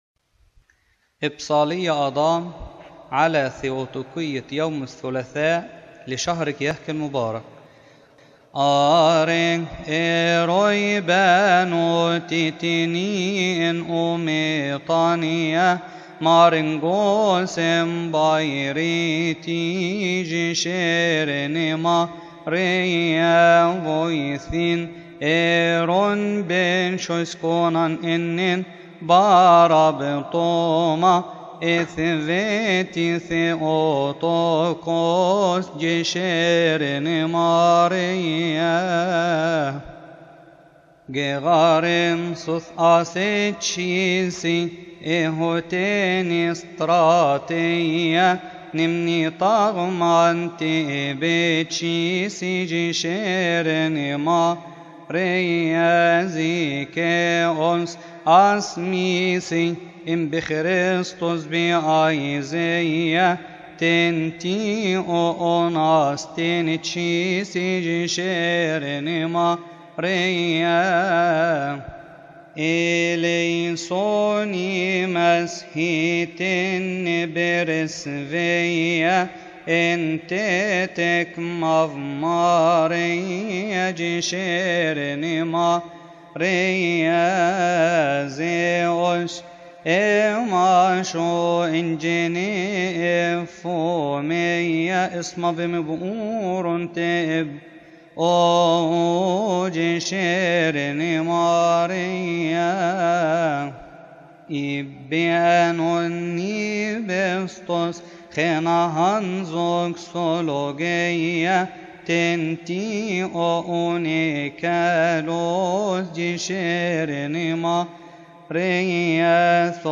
تقال في تسبحة نصف الليل بشهر كيهك
ابصالية آدام علي ثيؤطوكية يوم الثلاثاء